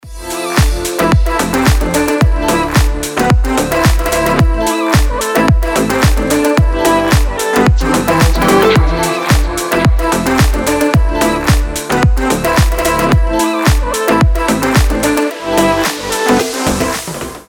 Просто музыка